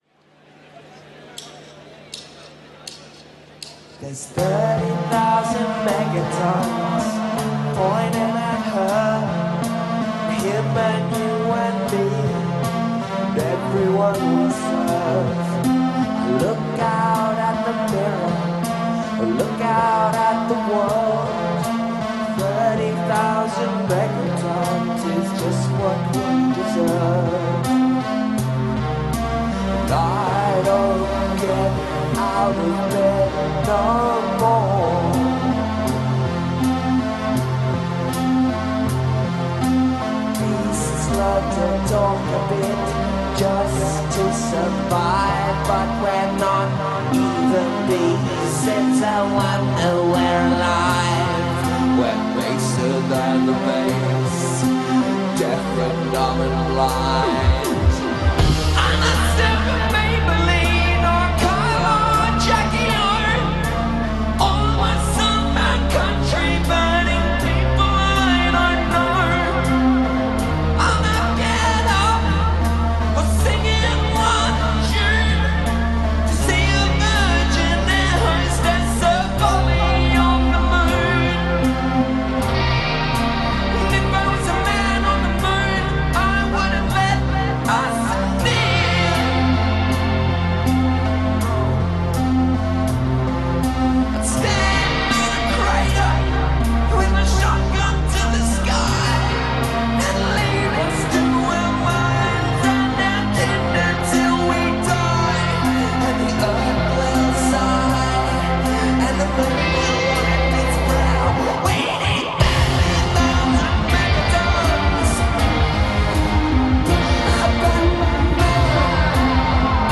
Australian Psych aggregation